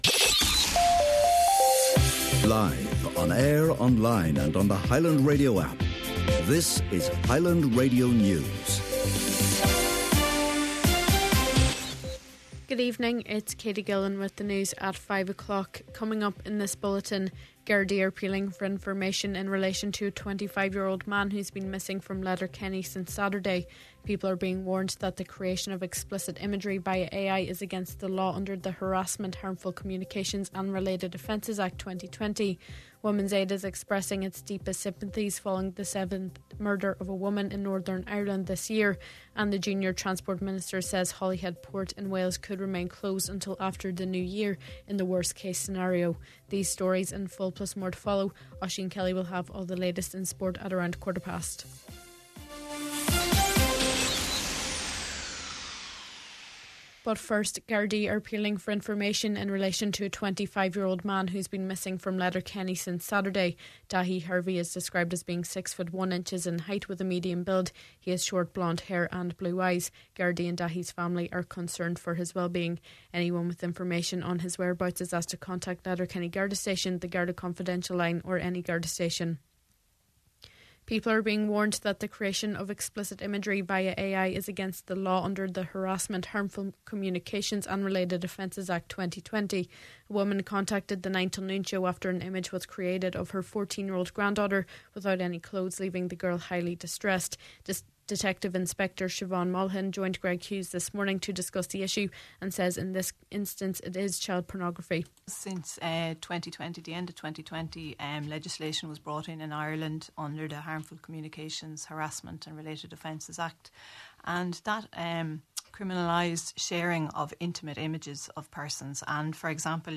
Main Evening News, Sport and Obituaries – Monday December 16th